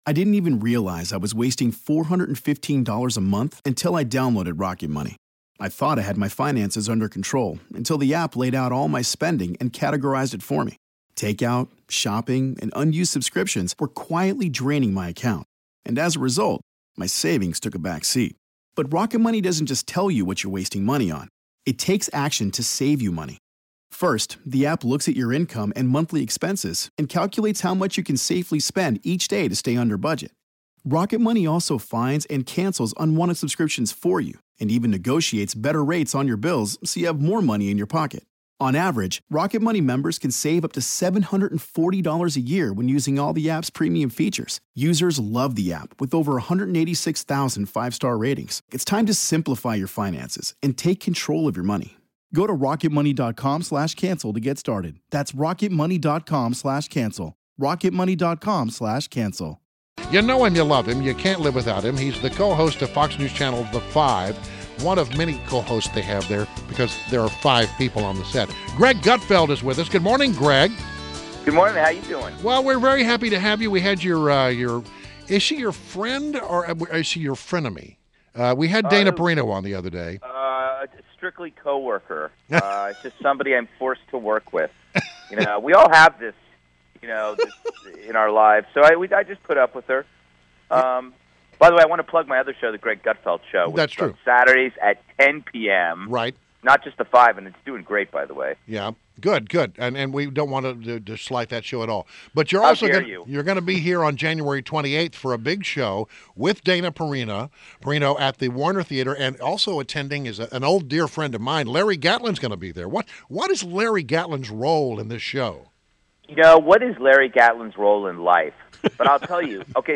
WMAL Interview - GREG GUTFELD - 12.16.16